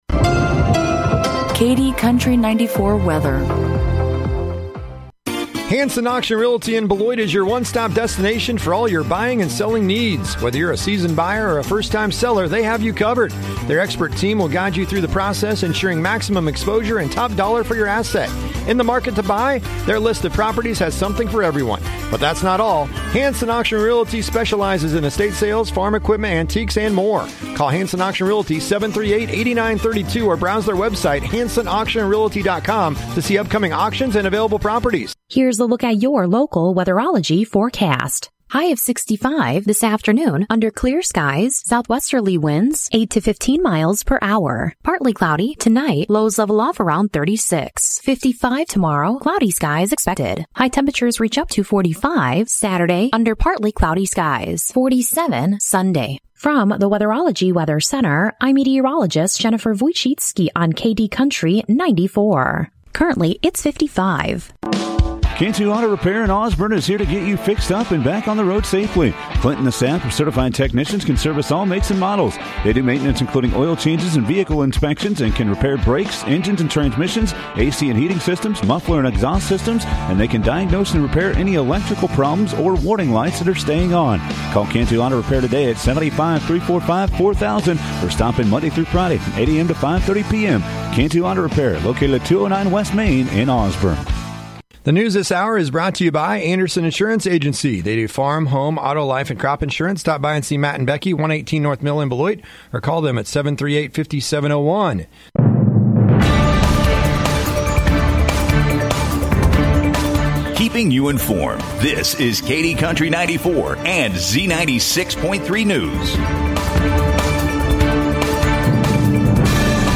KD Country 94 Local News, Weather & Sports - 12/7/2023